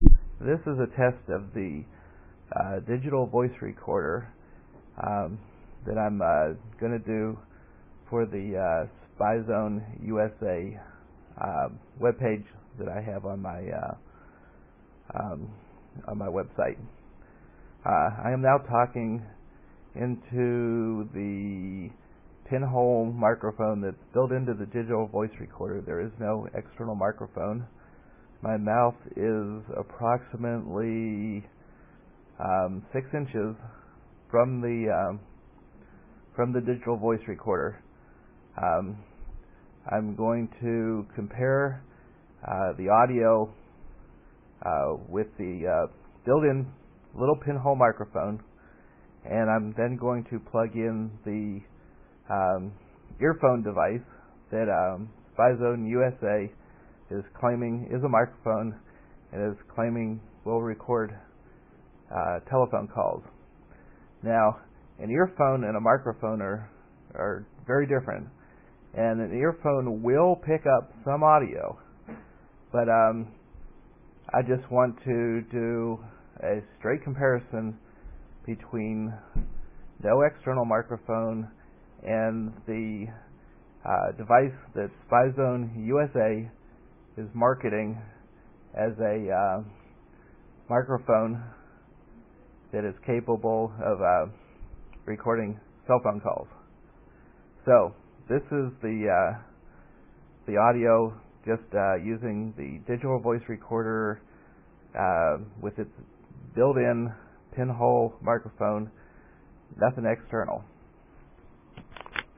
I recorder 2 MP3 files using the same digital voice recorder.
These files are unaltered - straight off my system.
Using the internal pin hole mic built into the voice recorder - talking 6 inches away from the mic -
This is what it sounds like with the internal built in mic.
builtinmic.mp3